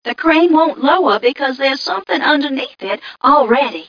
1 channel
mission_voice_m1ca045.mp3